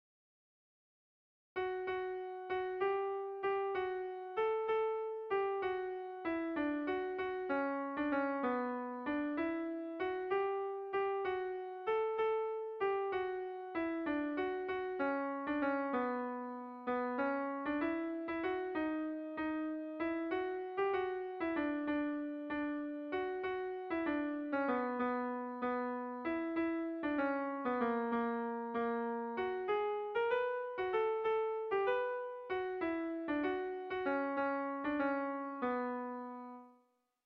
Bertso melodies - View details   To know more about this section
ABDE